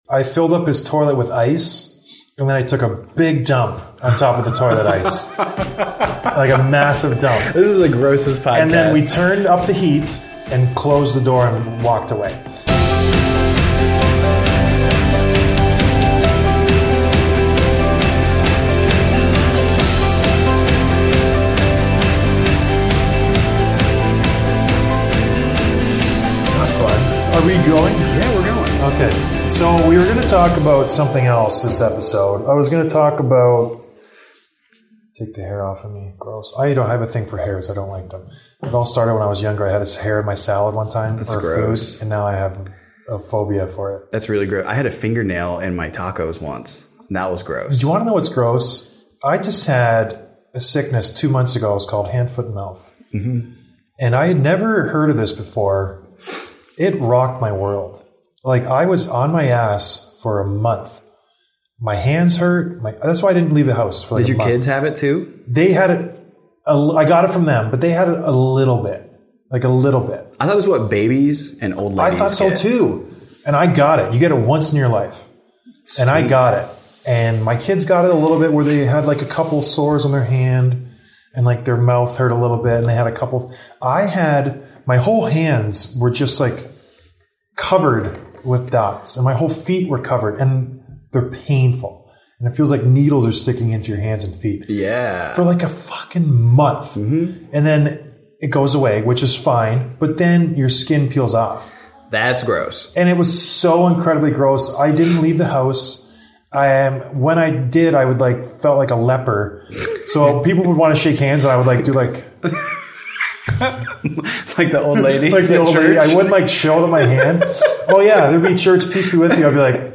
I am recording with my friend